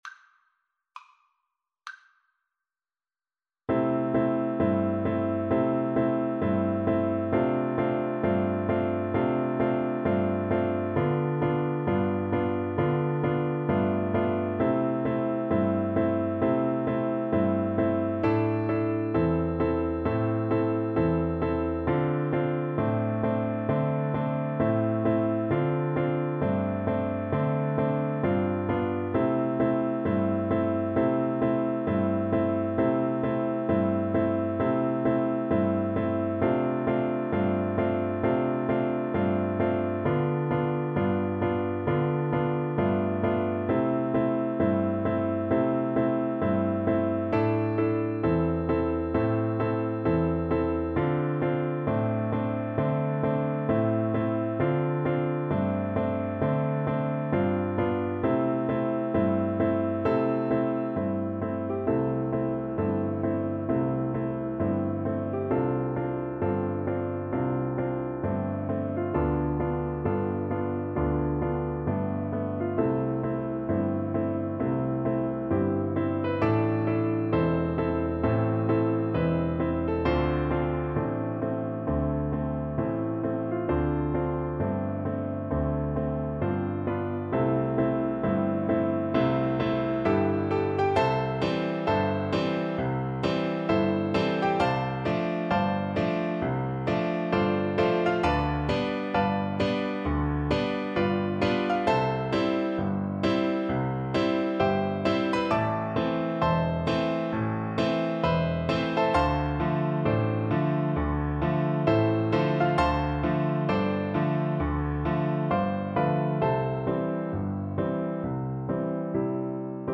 Blues Tempo (=66)
Jazz (View more Jazz Violin Music)